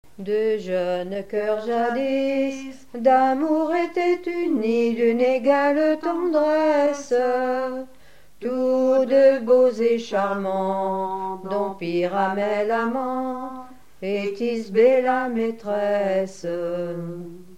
Mémoires et Patrimoines vivants - RaddO est une base de données d'archives iconographiques et sonores.
chantée en duo
Pièce musicale inédite